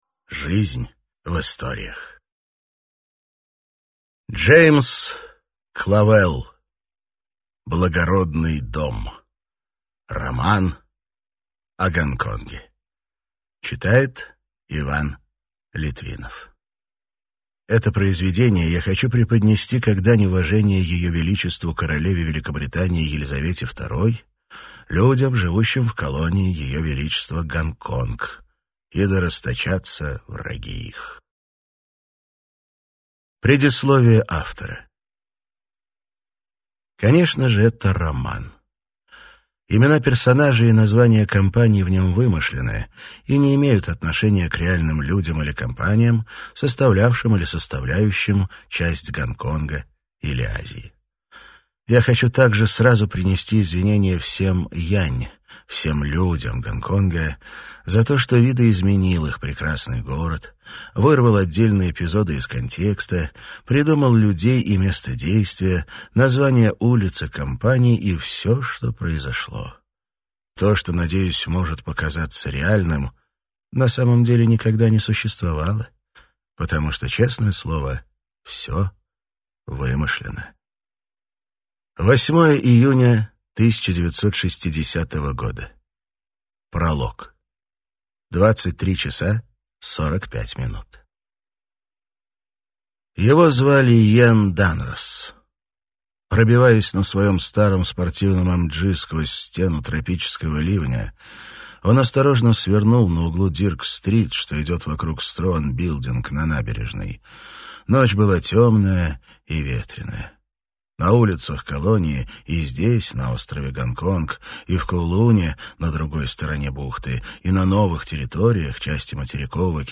Аудиокнига Благородный дом. Часть 1 | Библиотека аудиокниг